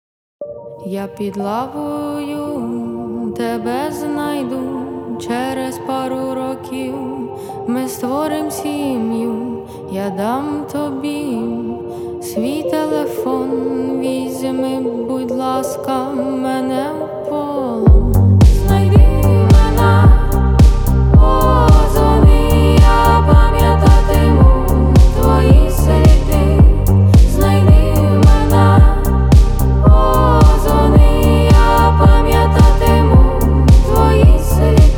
Жанр: Поп / Инди / Украинский рок / Украинские
# Indie Pop